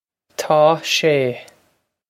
Pronunciation for how to say
Taw shay
This is an approximate phonetic pronunciation of the phrase.